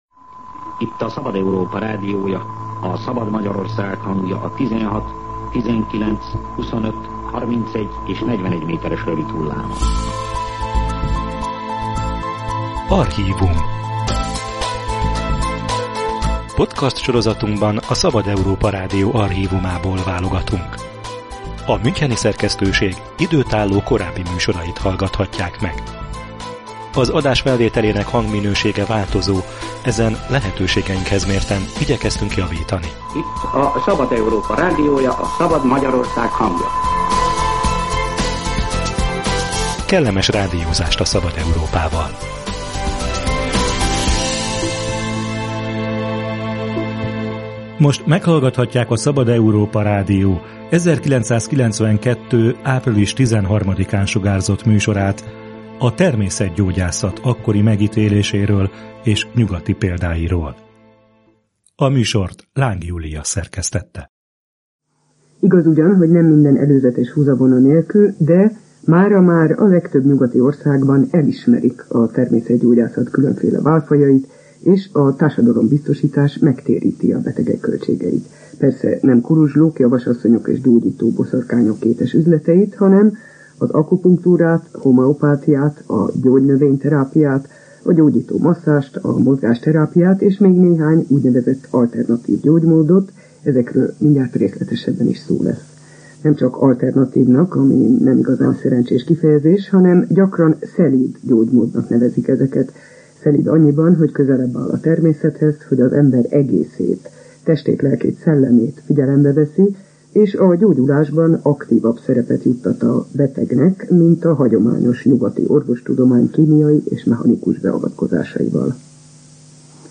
Akupunktúra, homeopátia, gyógynövény-terápiát, masszázs – a kilencvenes évek elején kezdtek széles körben terjedni az alternatív gyógymódok. Erről készített összeállítást a Szabad Európa Rádió 1992-ben, hogy tisztázza a kuruzslás és a valódi, gyógyulást ígérő kezelések közötti különbséget.